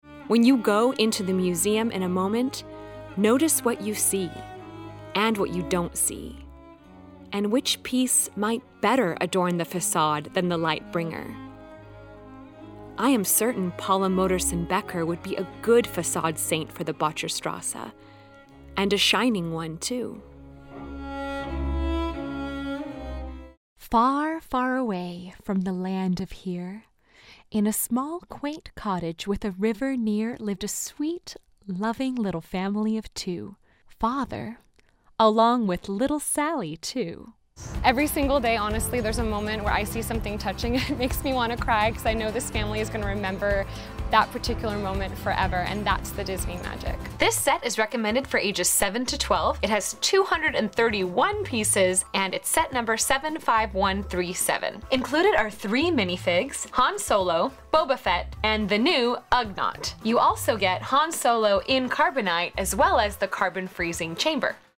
VOICE
Voice: Soprano
Voice Character: Bright, Pleasant
DIALECTS: Southern, Irish, Cockney, Standard British